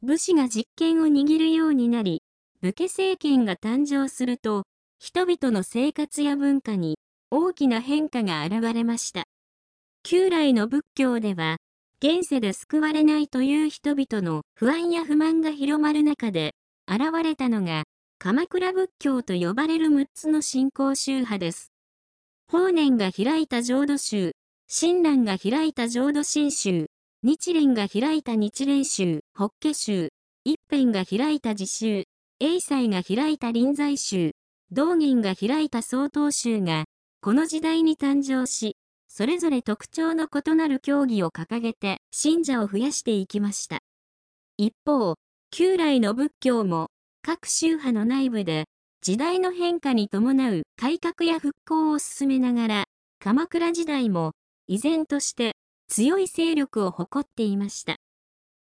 読み上げ音声